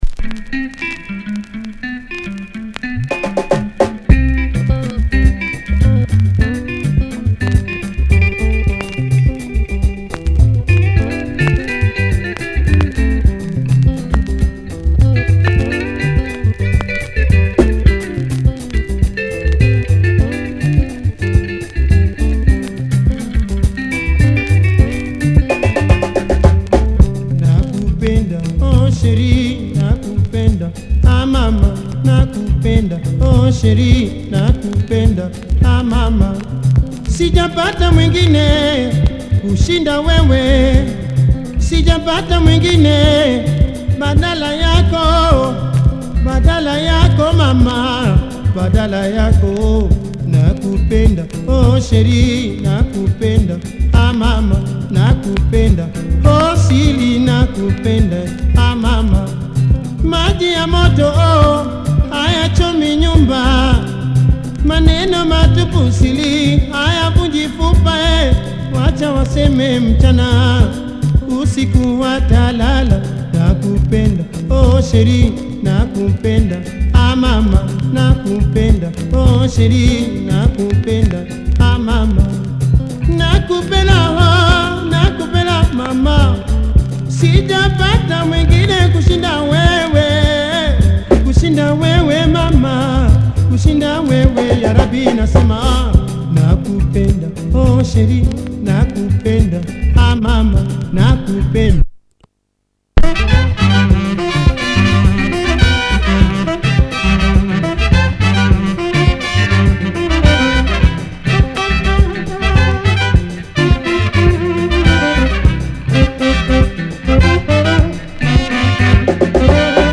Quality Kenya Soukous